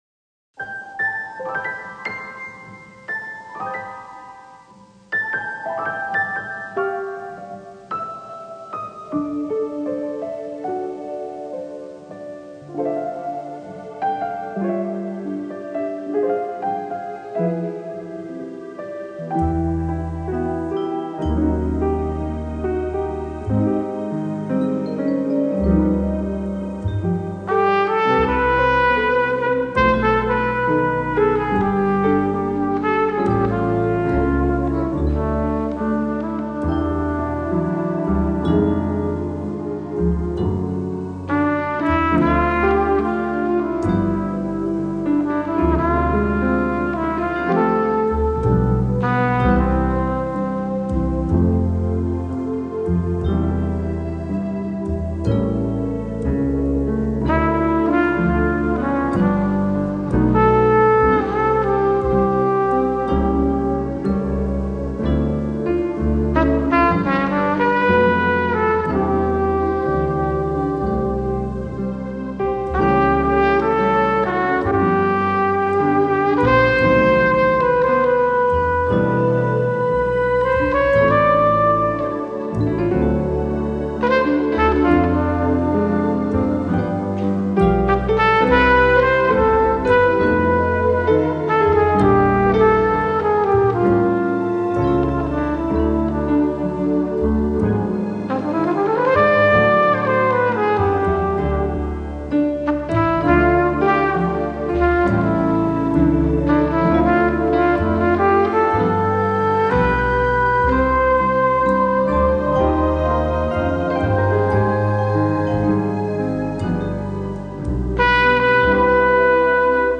It’s a real piano recorded